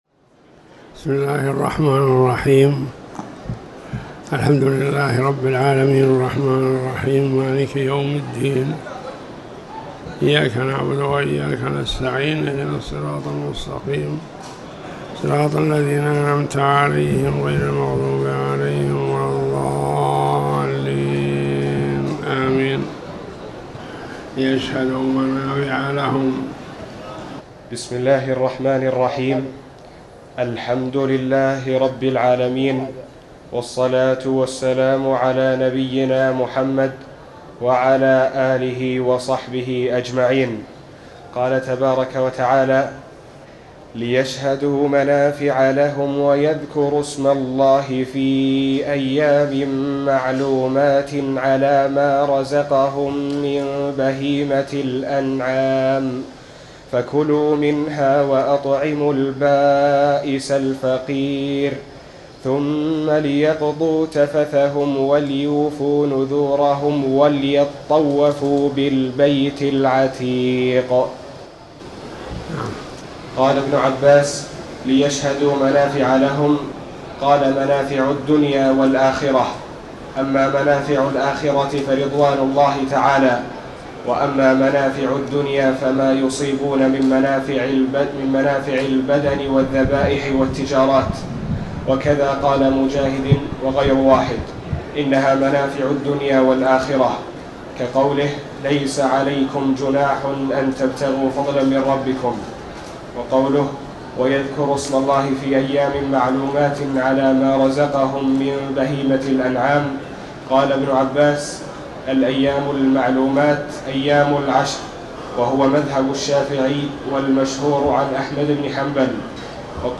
تاريخ النشر ٢٣ ذو القعدة ١٤٤٠ هـ المكان: المسجد الحرام الشيخ